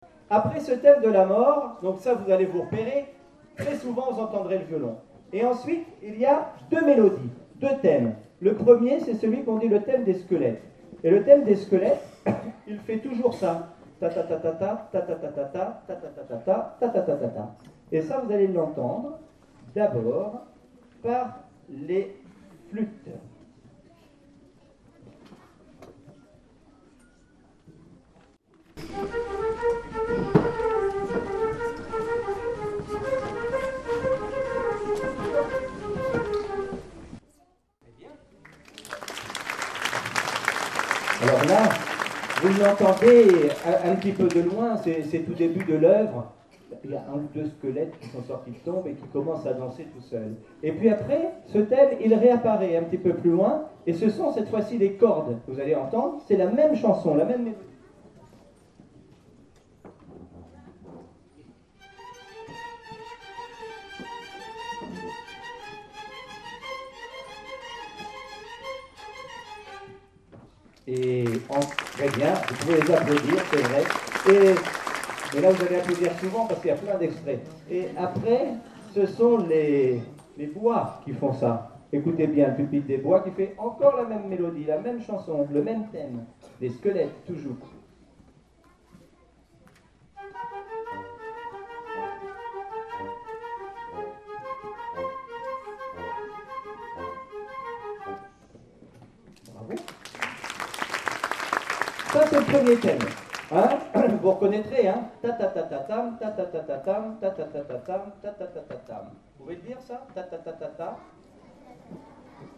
Album: Concert pédagogique 2011